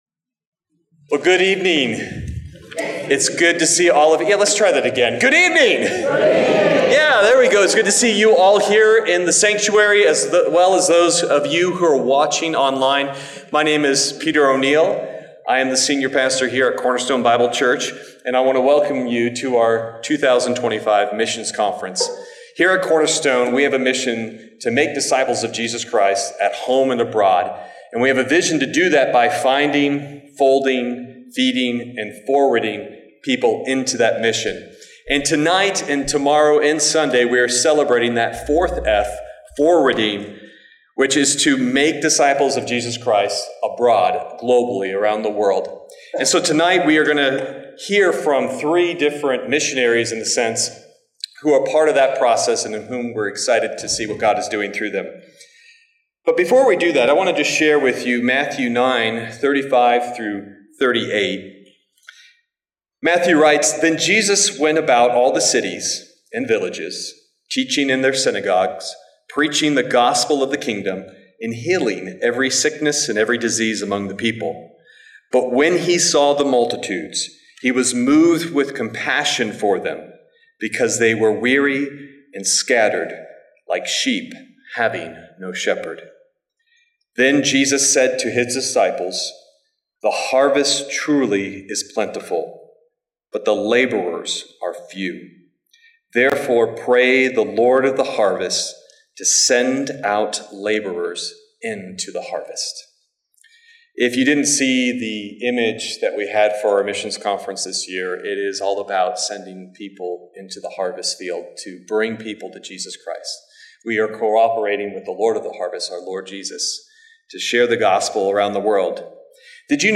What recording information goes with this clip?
Friday - Missions Conference 2025